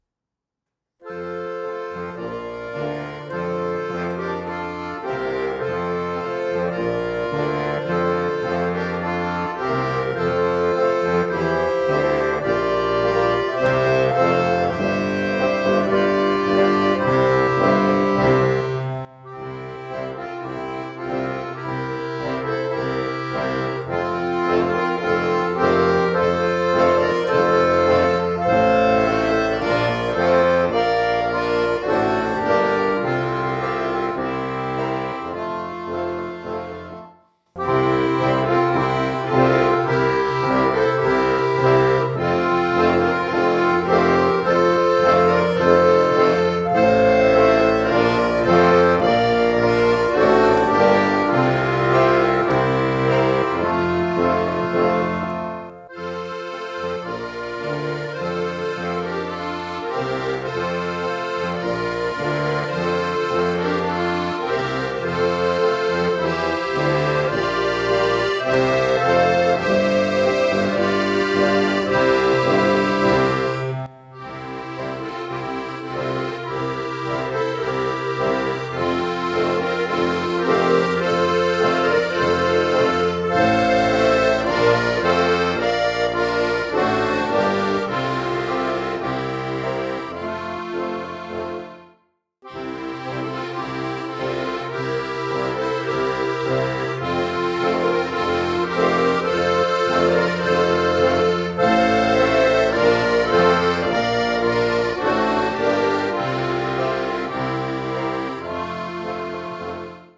Melodie einer sizilianischen Volksweise